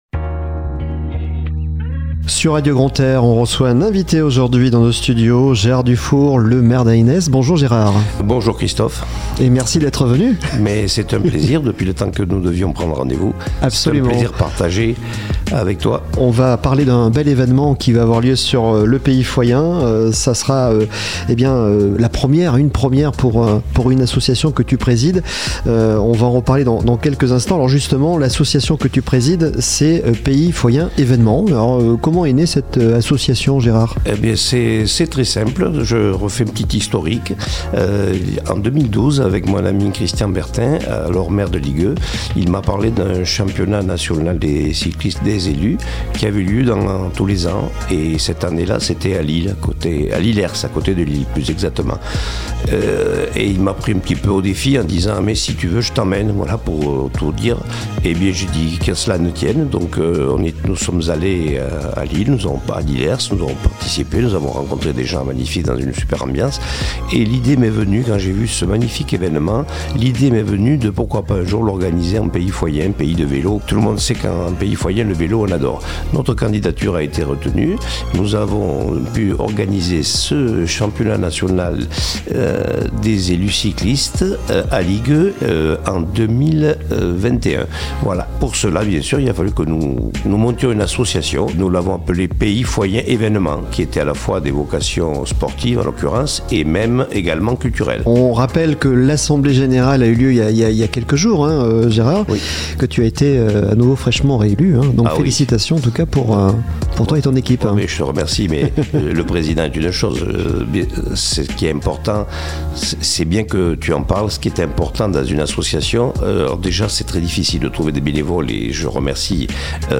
Itw Gérard DUFOUR de l'association Pays Foyen Evènements
On revient sur le rendez-vous avec Gérard DUFOUR le Maire d'Eynesse et président de l'association "Pays Foyen Evènements" sur Radio Grand "R" ????